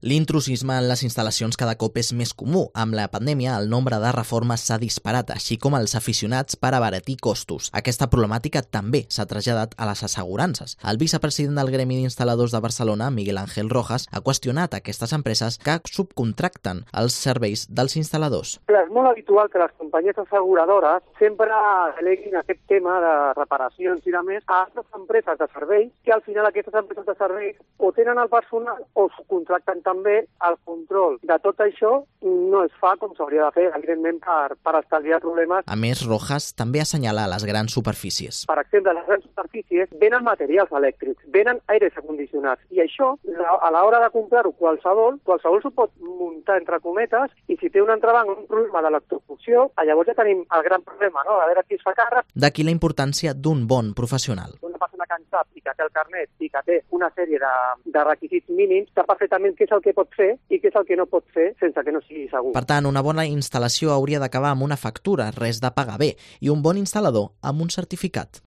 crónica sobre intrusismo en el sector de los electricistas